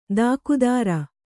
♪ dākudāra